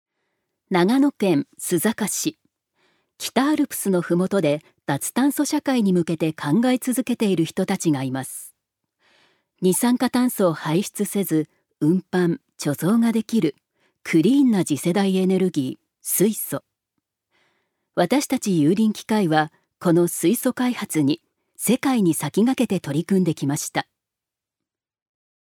女性タレント
ナレーション３